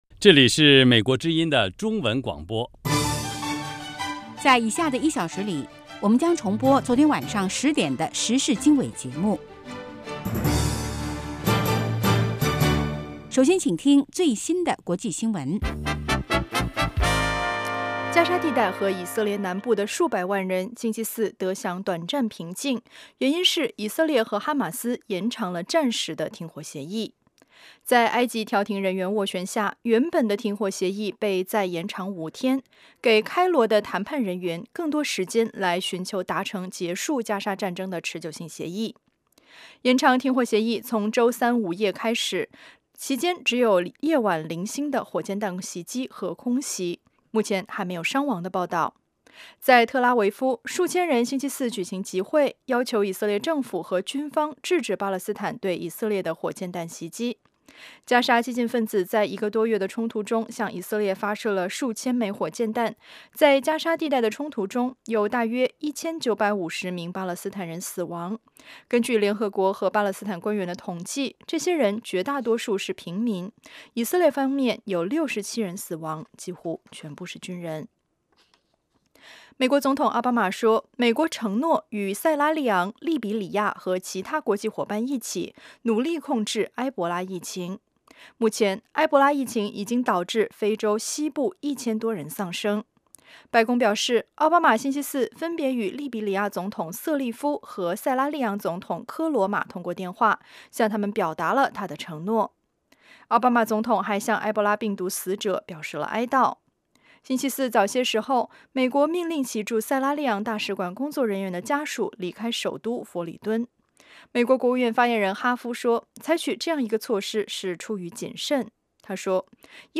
早8-9点广播节目